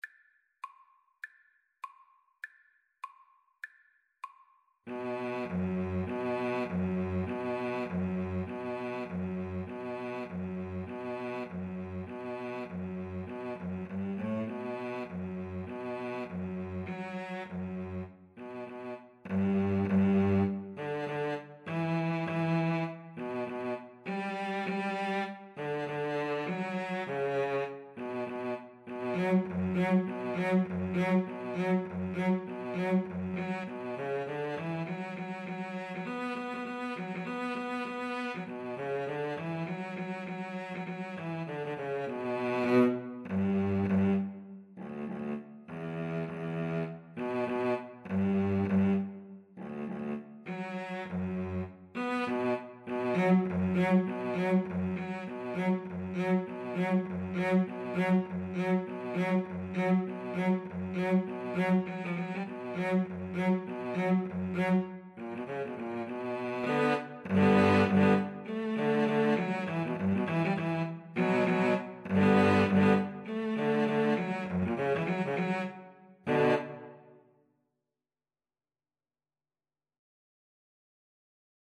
Free Sheet music for Viola-Cello Duet
B minor (Sounding Pitch) (View more B minor Music for Viola-Cello Duet )
Firmly, with a heart of oak! Swung = c.100
2/2 (View more 2/2 Music)
Traditional (View more Traditional Viola-Cello Duet Music)
wellerman_VLAVLC_kar1.mp3